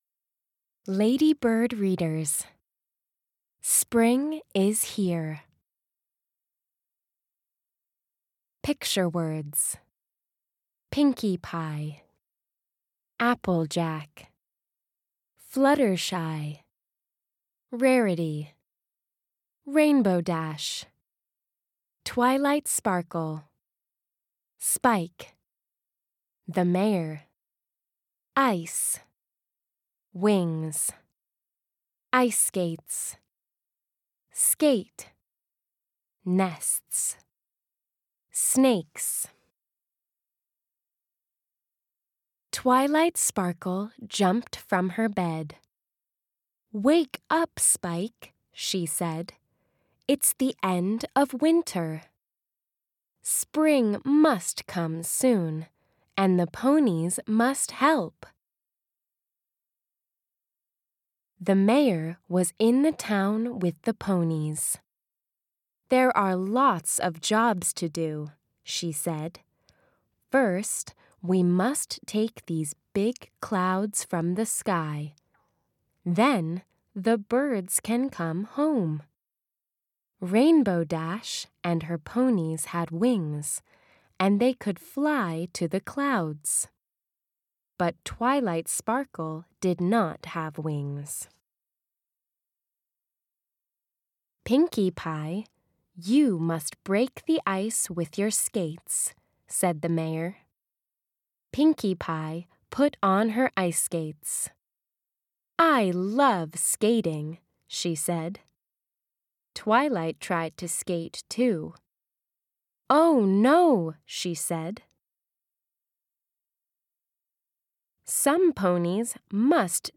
Audio US
Reader -